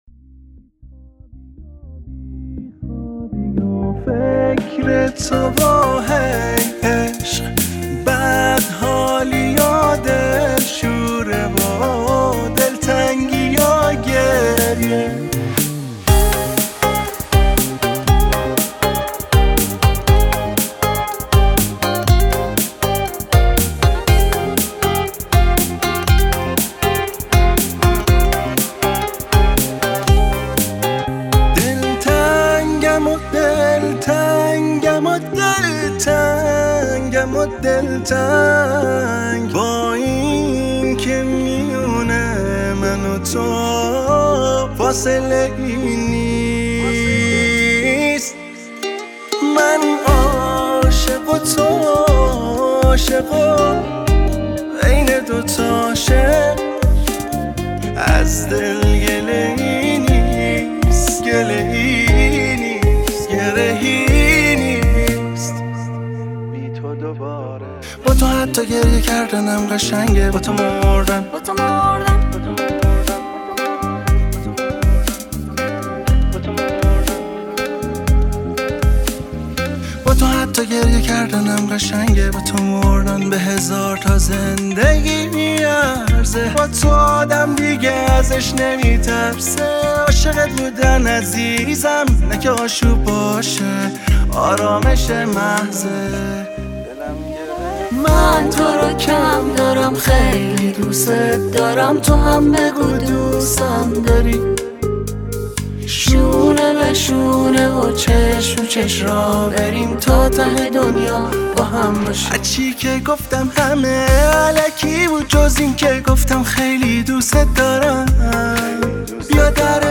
آهنگ پاپ